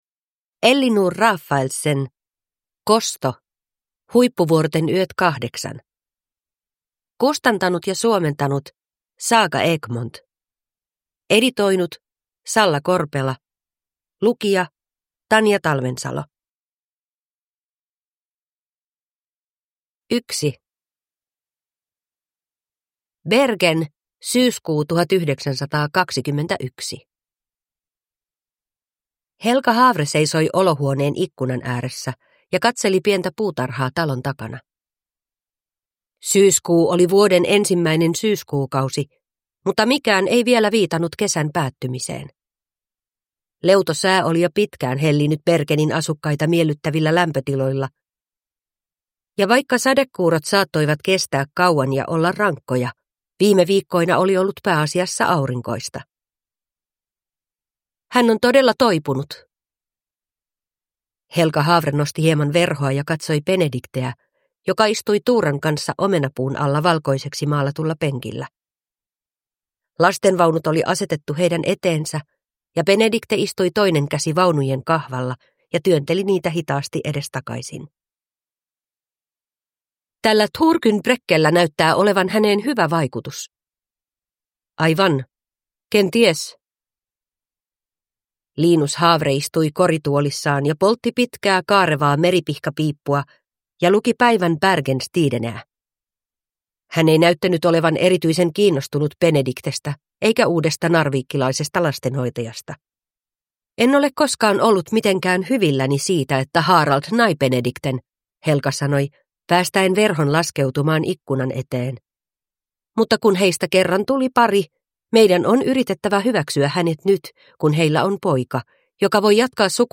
Kosto – Huippuvuorten yöt 8 (ljudbok) av Ellinor Rafaelsen